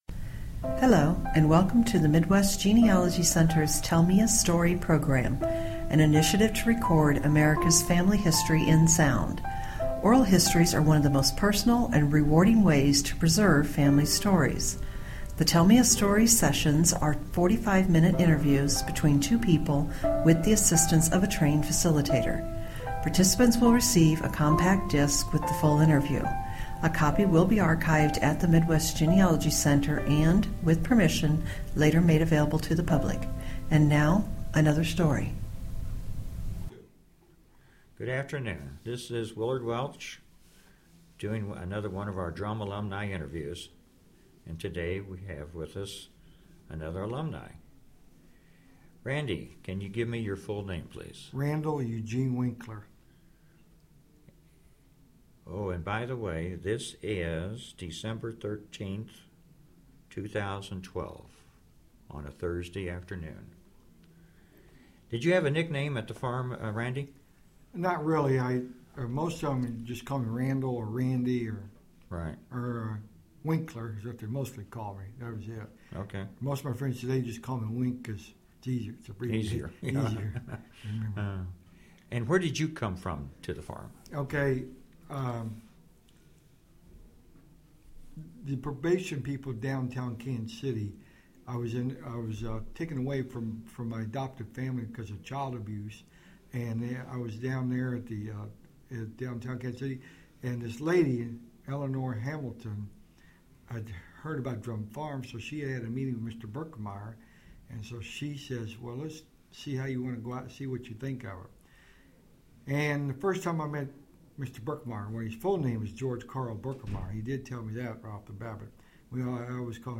Drumm Institute Oral Histories